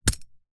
playerHit.mp3